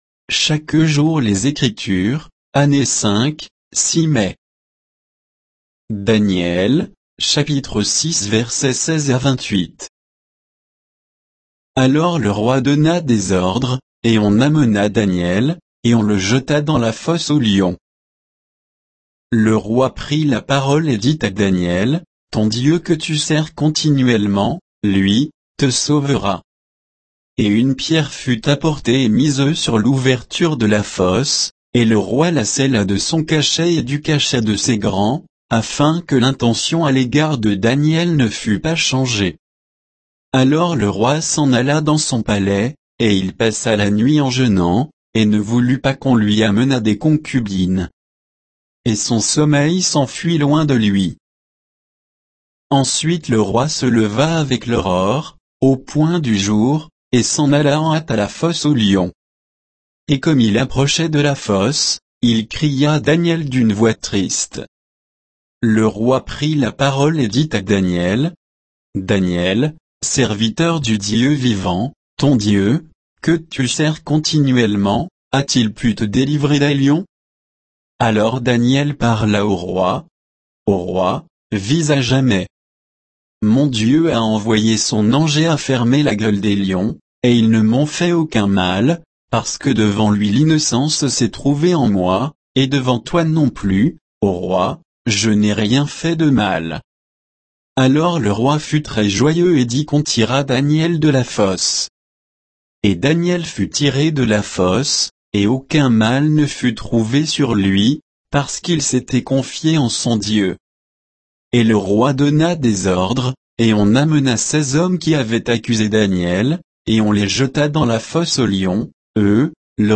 Méditation quoditienne de Chaque jour les Écritures sur Daniel 6